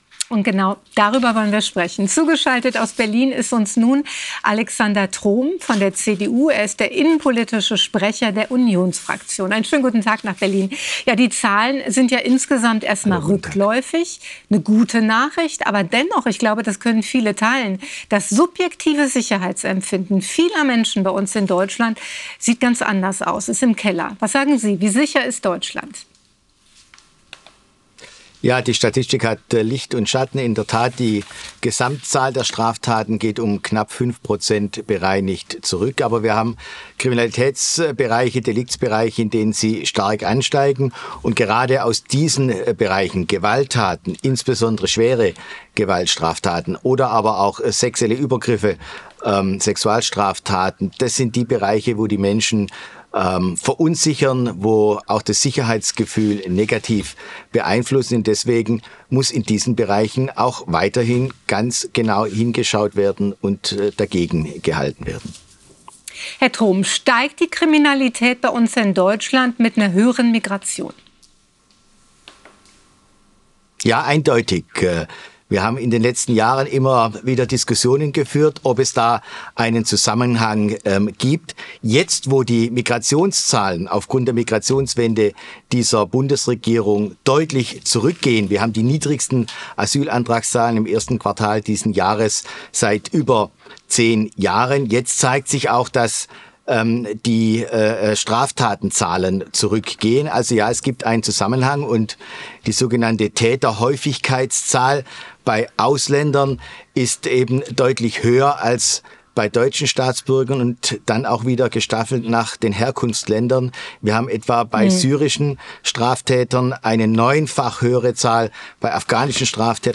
Im ntv-Interview erklärt er, mit welchen Maßnahmen die